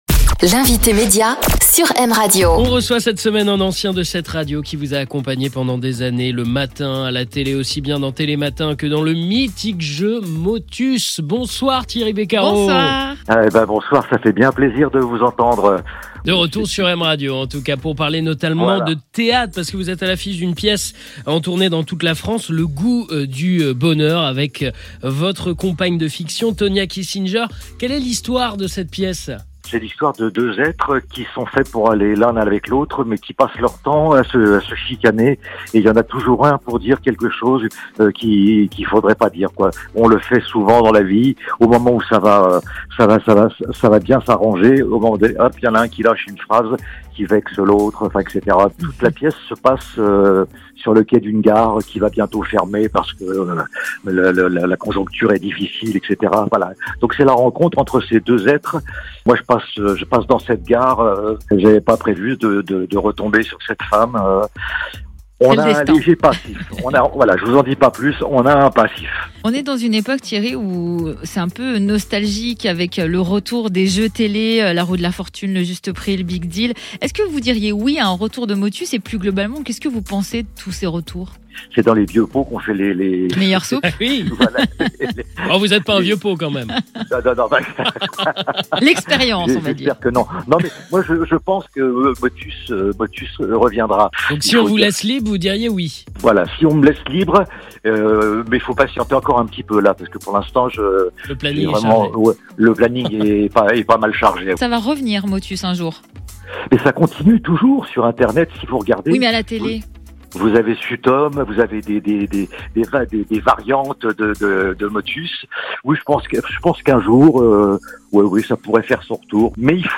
Retrouvez en podcast l'interview de l'invité de cette semaine